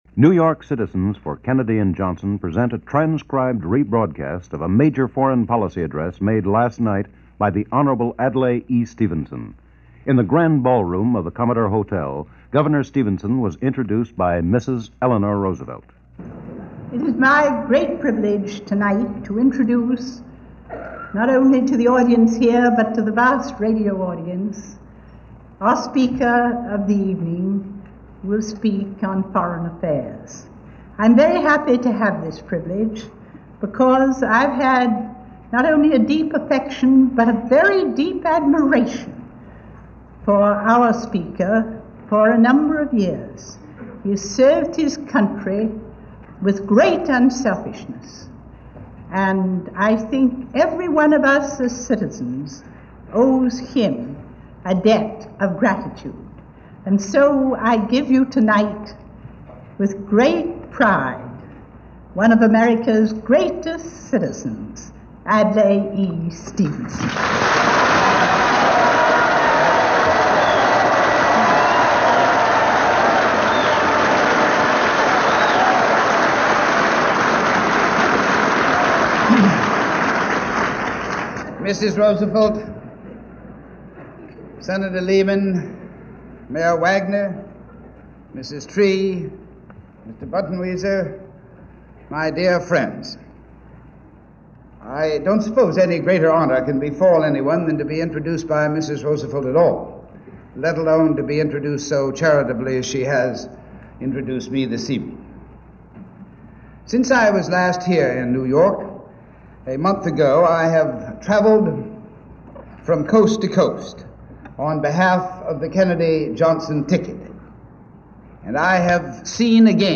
Adlai Stevenson, giving a Foreign Policy Address at the Grand Ballroom of the Commodore Hotel in New York on behalf of the Kennedy/Johnson campaign of 1960, and recorded on October 19, 1960 for broadcast the following day. Stevenson was introduced by former First Lady Eleanor Roosevelt.